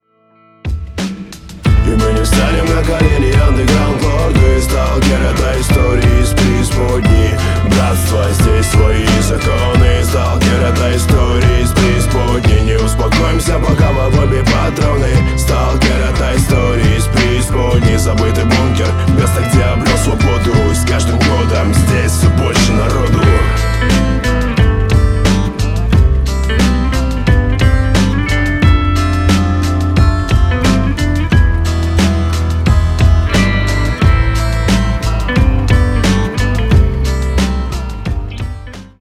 • Качество: 320, Stereo
гитара
мужской вокал
Rap-rock
русский рэп